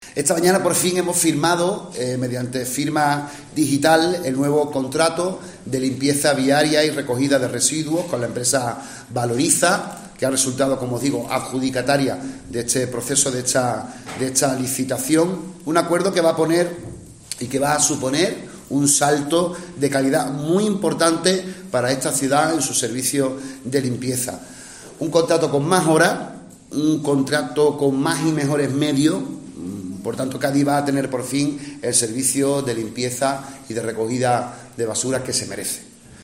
Tras la rúbrica, que se ha llevado a cabo de forma telemática, el regidor gaditano ha ofrecido una rueda de prensa para adelantar algunos detalles del nuevo acuerdo, que entrará en vigor el próximo 1 de febrero.